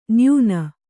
♪ nyūna